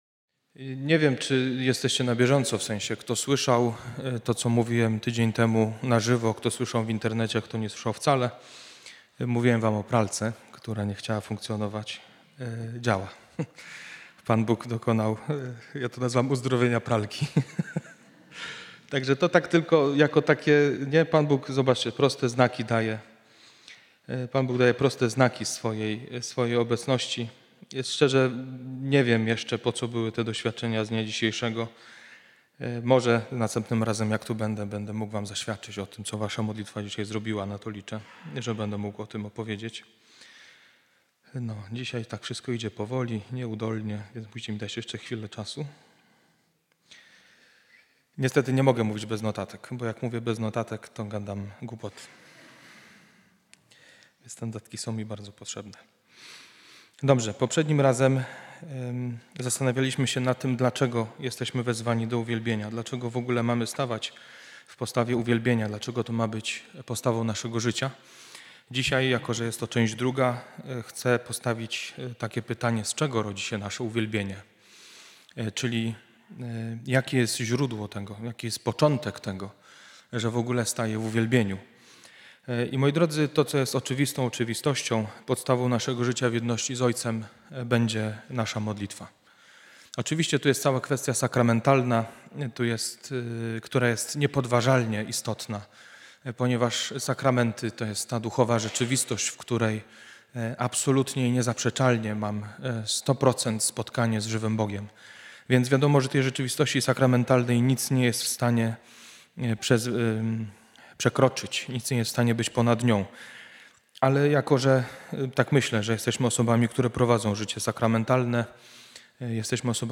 Konferencja O modlitwie uwielbienia wygłoszona dla GUBM w dniu 21.02.2018